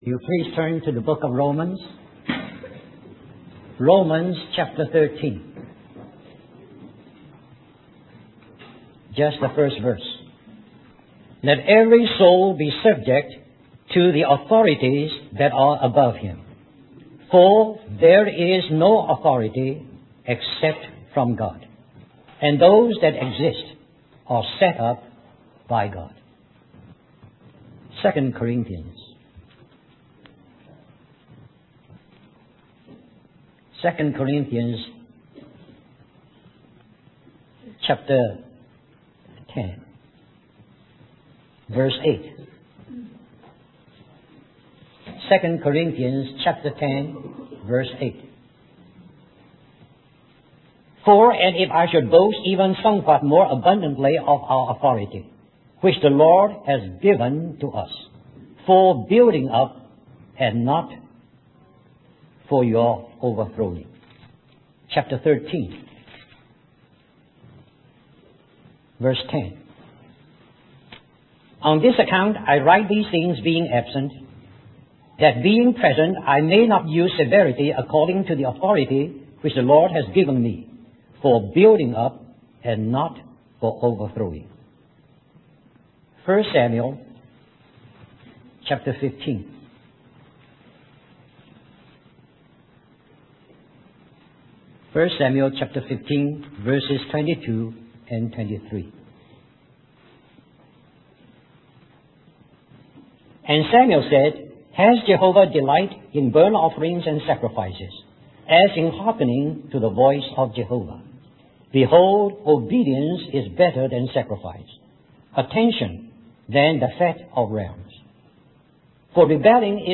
In this sermon, the preacher emphasizes the concept of authority in serving. He refers to Mark 10:45, where Jesus states that he came not to be served, but to serve and give his life as a ransom for many.